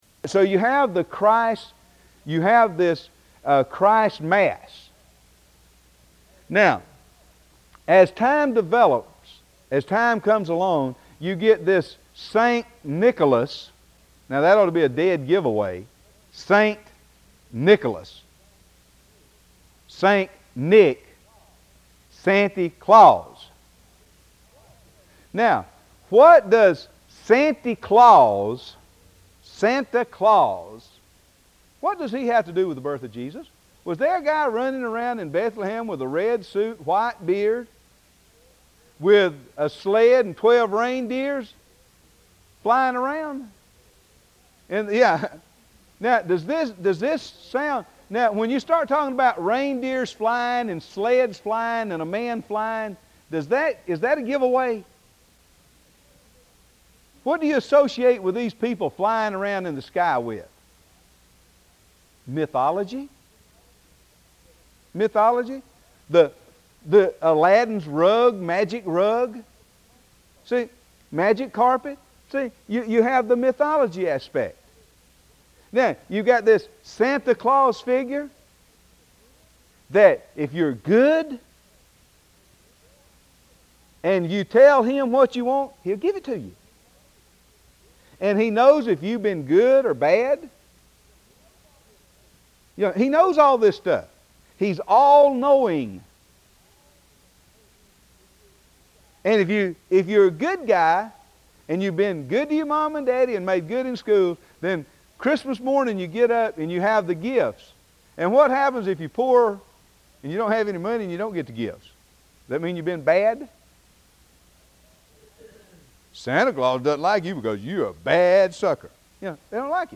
Lectures on the Judaism of Jesus and the Apostles and the Role of Non-Jews (Noahides) in this World and the World to Come